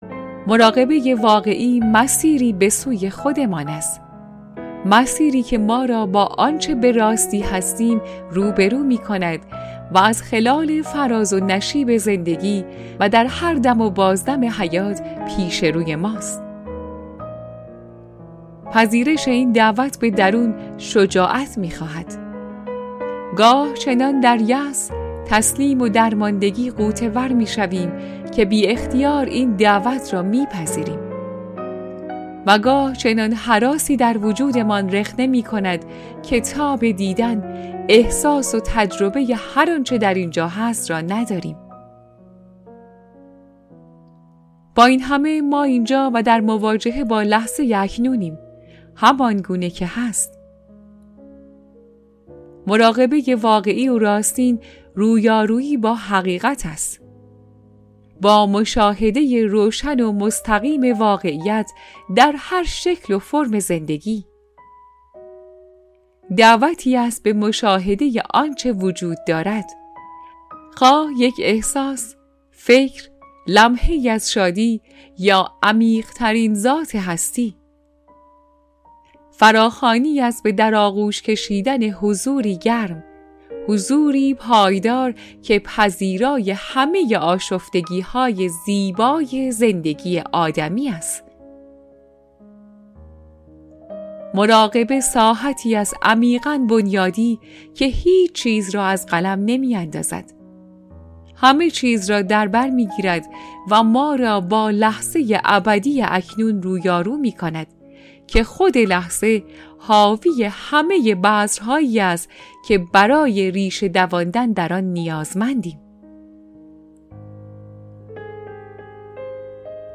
گزیده ای از کتاب صوتی